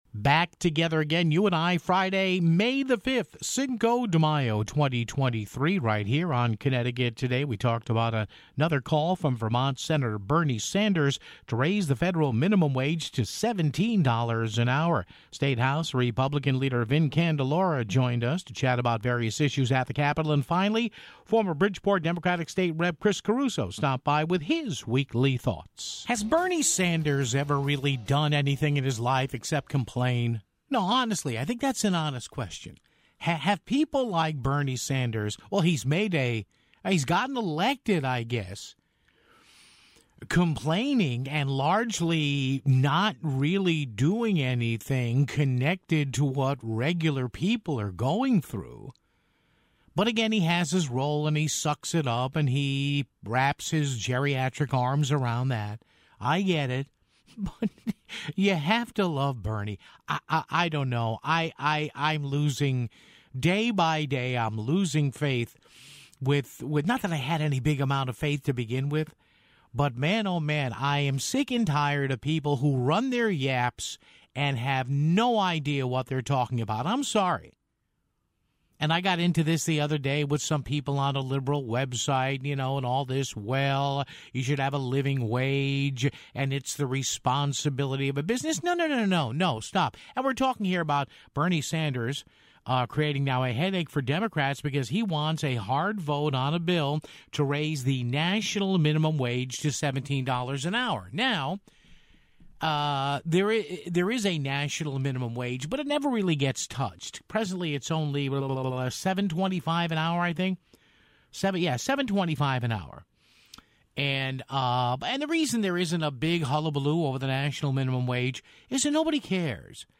State House GOP Leader Vin Candelora joined us to chat about various issues at the State Capitol (14:00).